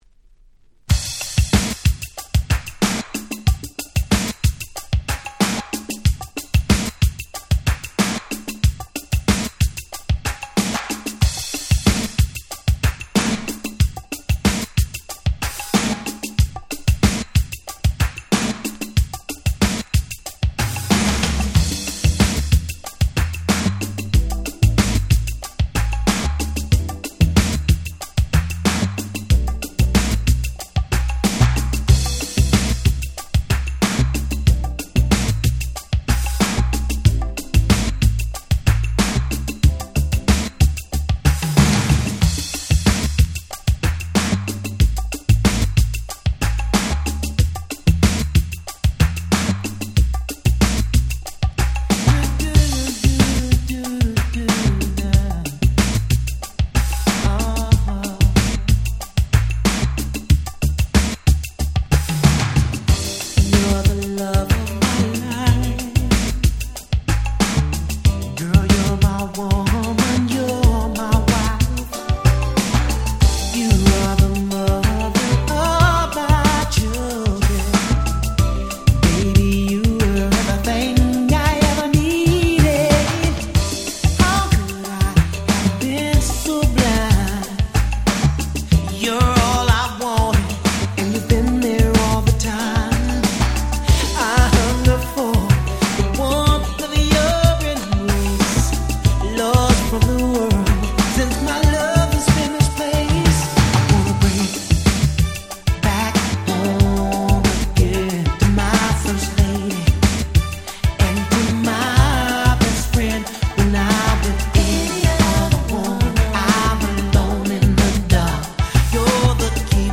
【Media】Vinyl 12'' Single
89' Smash Hit R&B !!
しっかりしたBeatながら甘い歌声が染み渡るGroovyなバラード。
明け方のフロアを素敵に彩るロマンティックな1曲です。